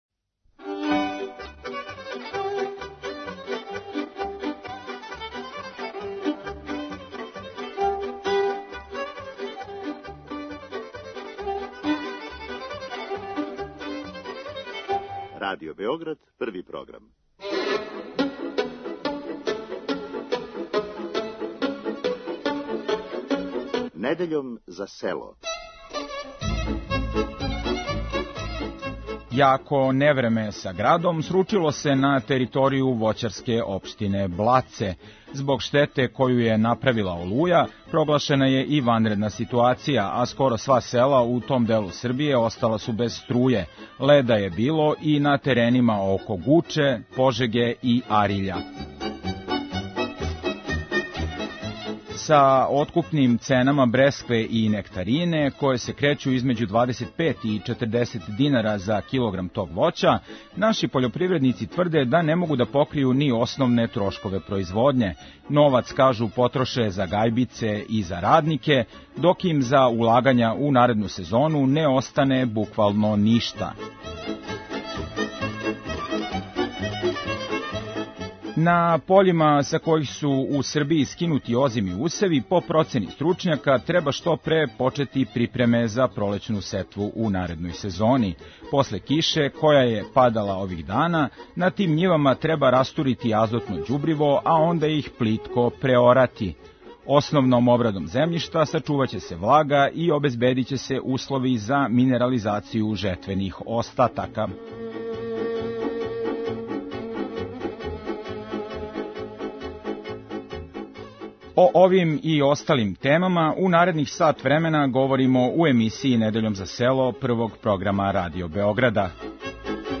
Уз остале теме из области пољопривреде у емисији Вас чека и традиционална народна музика из свих делова Србије.